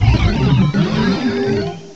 cry_not_blacephalon.aif